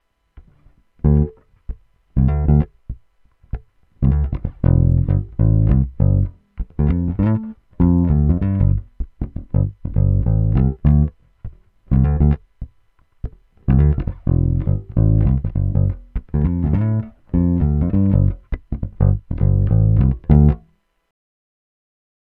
Clip 4 – SF Neck – Finger Style – Tone up
916-SF-Neck-Fingerstyle-Tone-UP.m4a